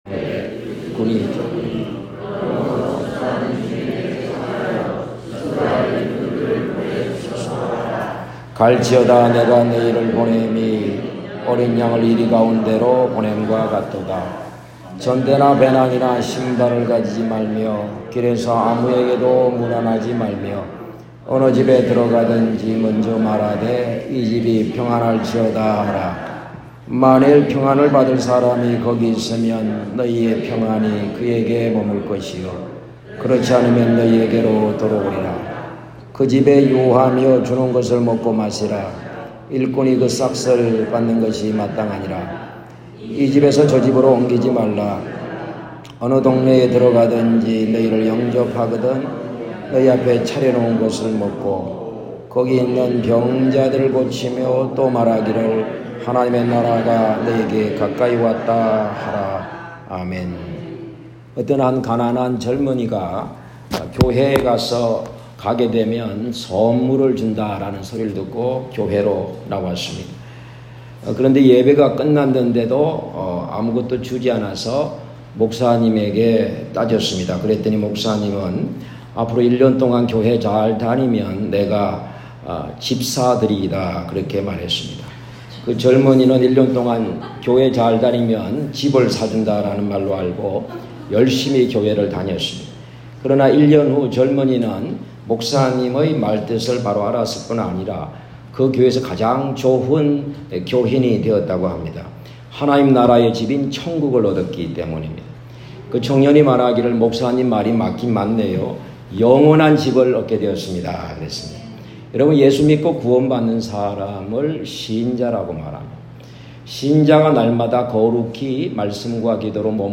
해외 단기선교사 파송식 본문: 눅 Luke 10:1-6 제목: 갈지어다 The sent
선교사-파송식-설교눅-10.m4a